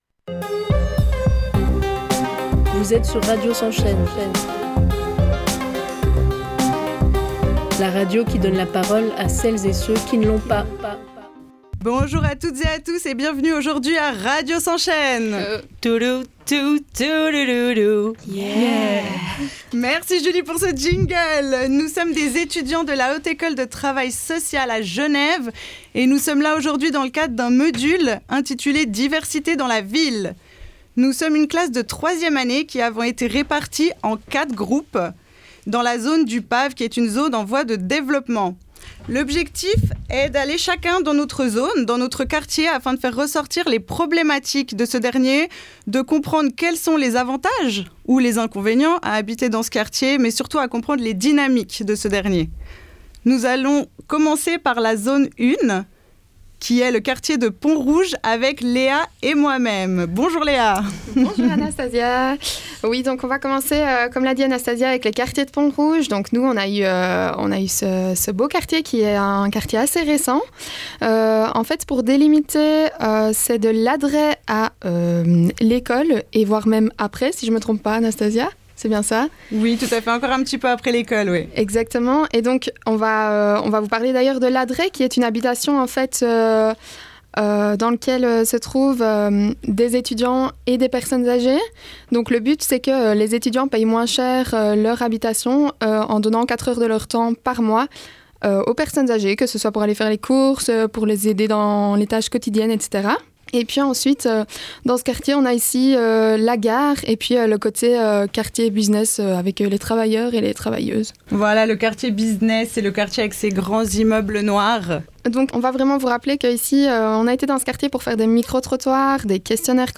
Une vingtaine d’étudiant.e.s sont aller à leur rencontre dans divers quartiers en chantier (Acacias, Pont-Rouge, Tours de Carouge) pour répondre à ce questionnement en réalisant des stands, des micro-trottoirs, des entretiens et en oeuvrant à des mises en lien, en réseau et en action.